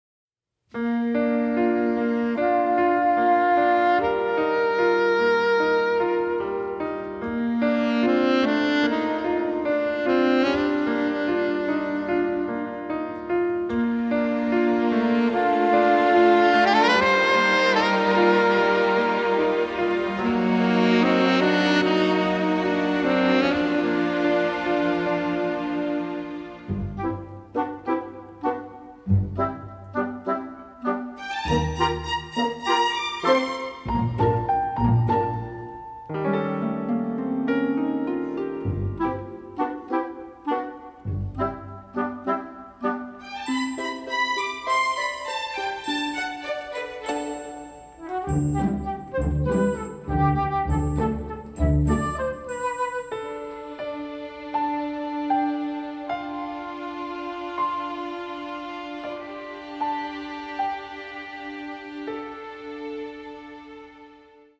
a bubbly score featuring lush orchestrations